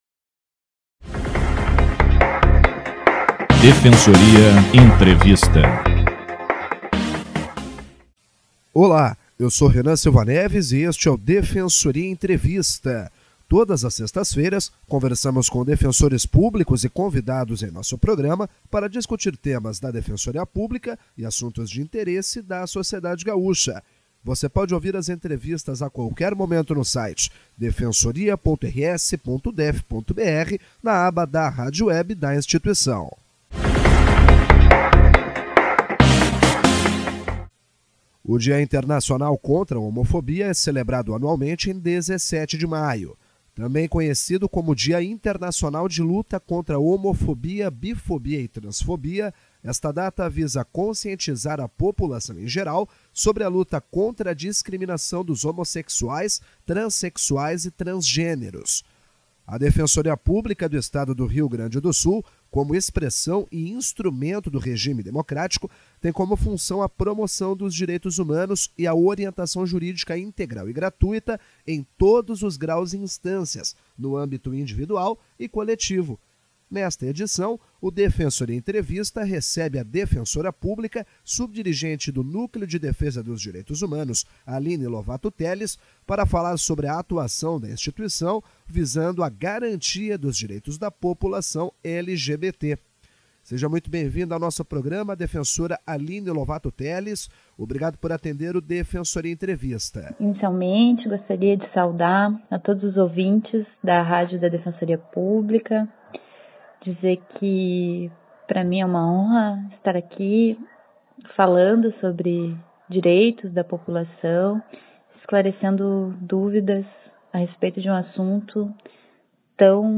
DPE Entrevista 25.05.2018